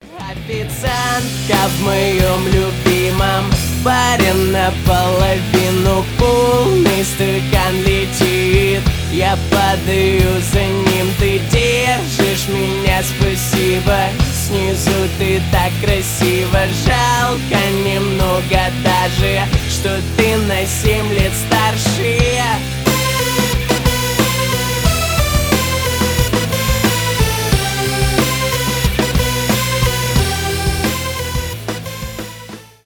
альтернатива rock